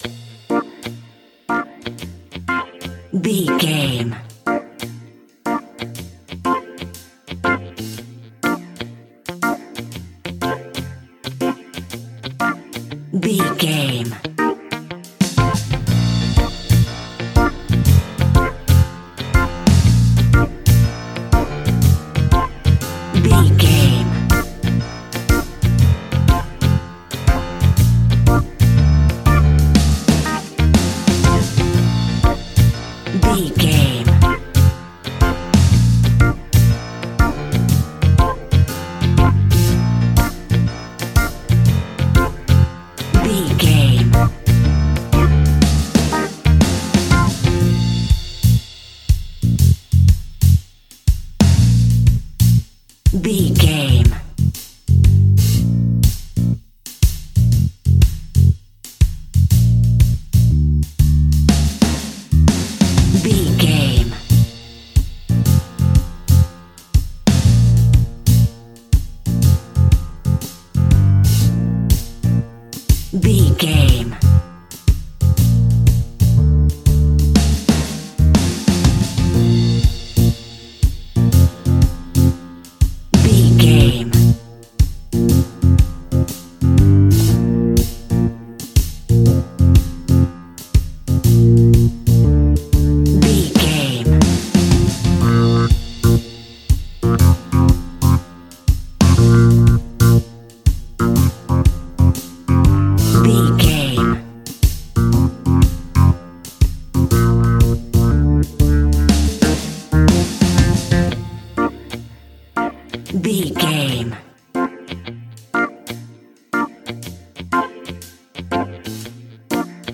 Hot summer sunshing reggae music for your next BBQ!
Ionian/Major
B♭
Slow
instrumentals
laid back
chilled
off beat
drums
skank guitar
hammond organ
percussion
horns